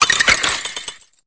Cri de Sorboul dans Pokémon Épée et Bouclier.